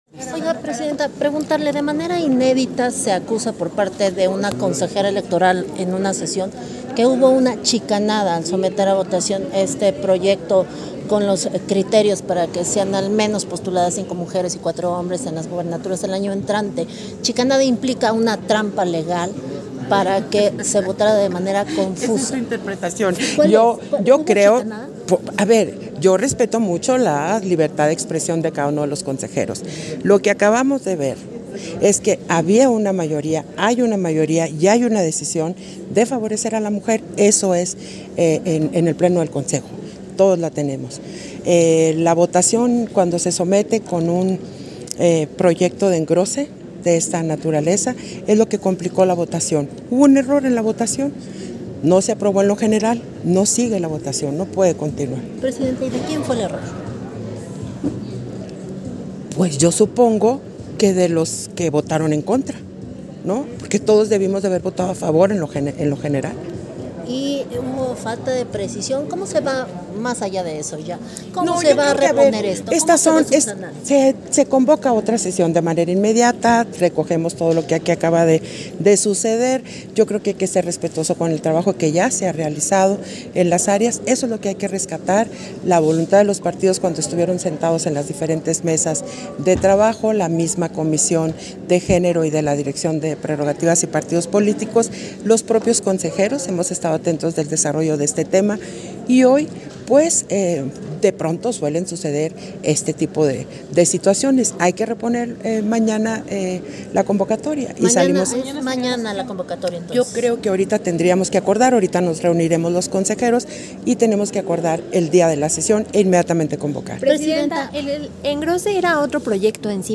Entrevista que concedió Guadalupe Taddei, a diversos medios de comunicación, al término de la Sesión Extraordinaria del Consejo General